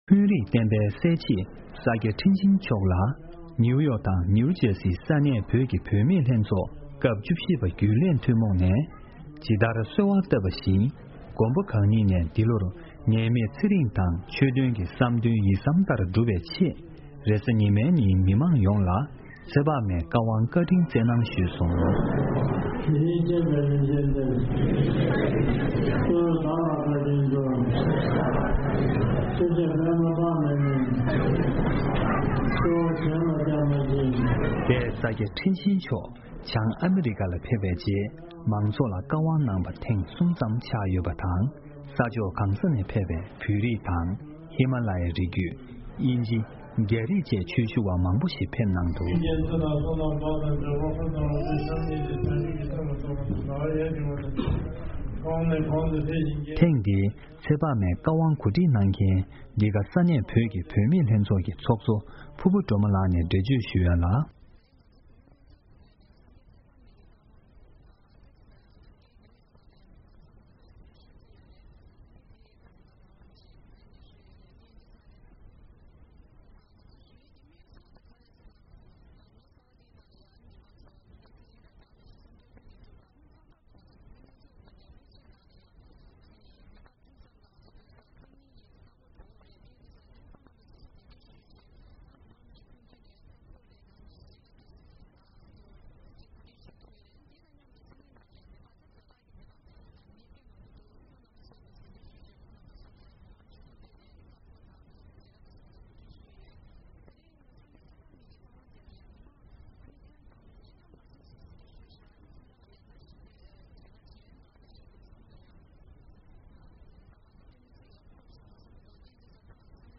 ནེའུ་ཡོག་ནས་བཏང་བའི་གནས་ཚུལ་ཞིག་